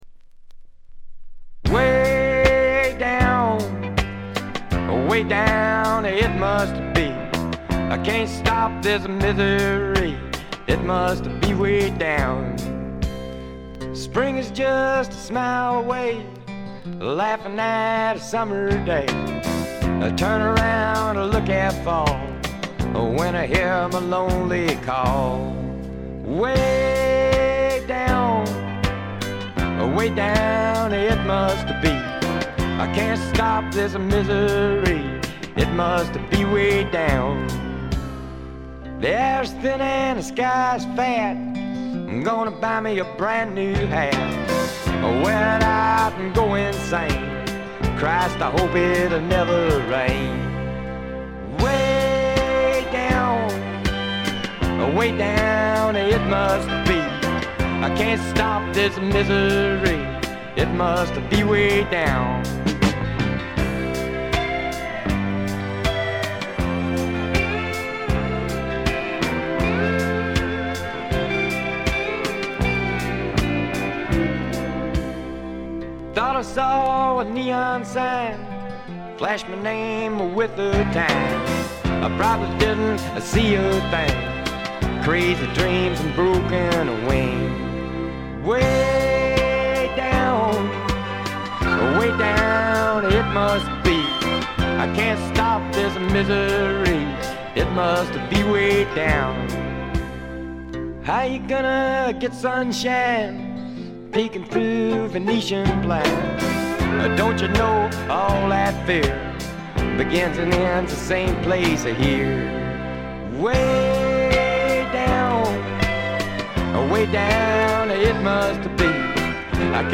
部分試聴ですが軽微なチリプチ少々程度。
試聴曲は現品からの取り込み音源です。
vocals, acoustic guitar